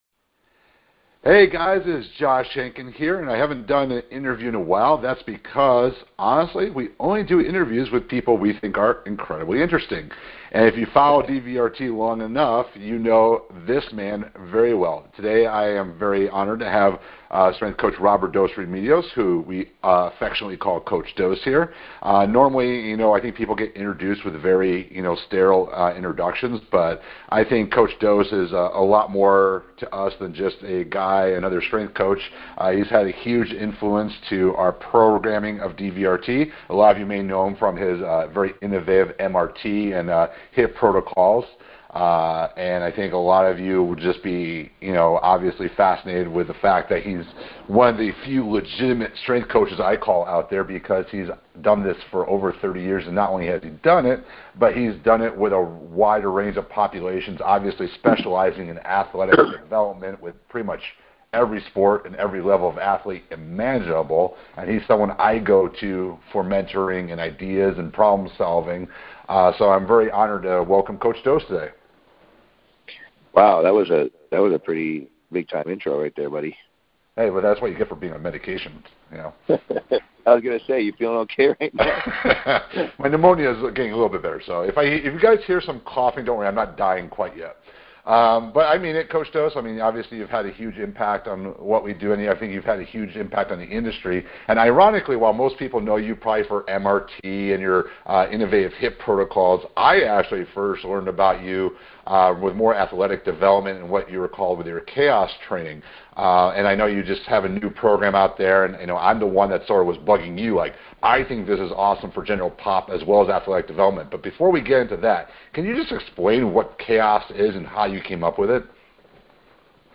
I have included an hour long recorded lecture (in both video and audio files) so that you can truly understand the rationale and background of CHAOS before diving into the drills.